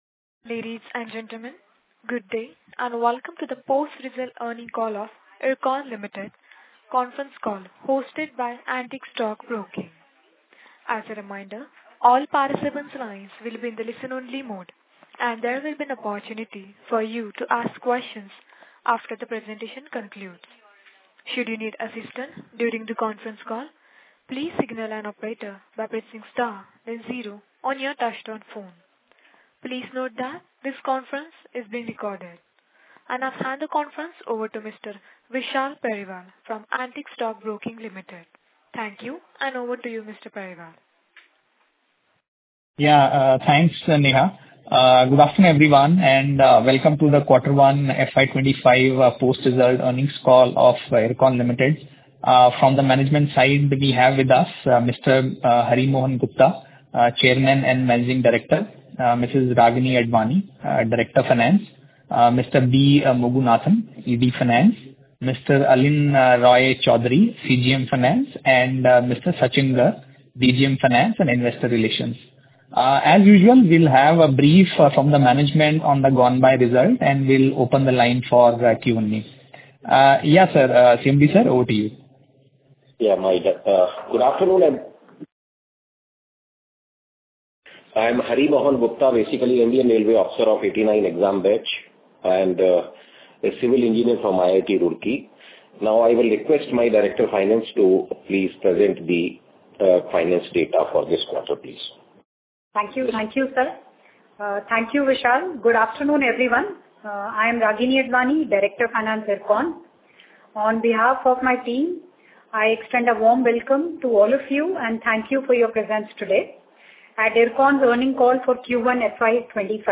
IRCON_Concall_Audio_Q1FY25.mp3